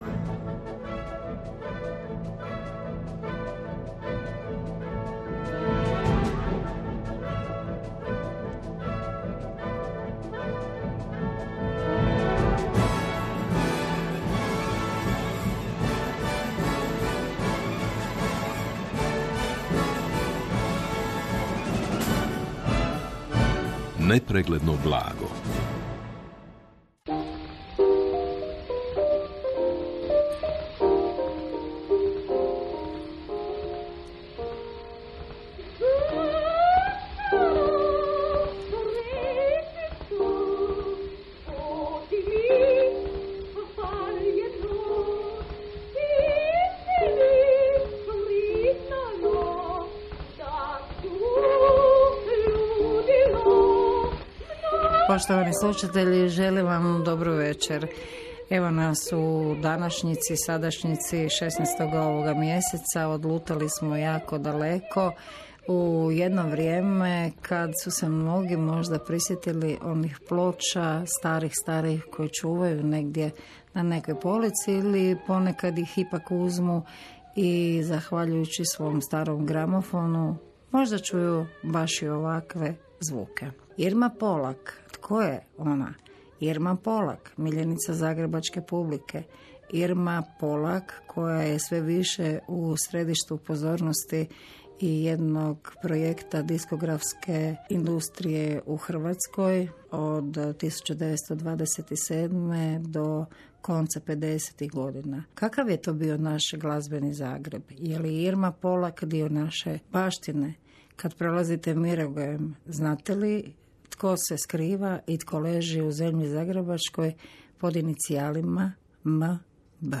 O Irmi Polak u radijskoj emisiji “Nepregledno blago” (Radio Sljeme, 16.6.2022.)|Institut za etnologiju i folkloristiku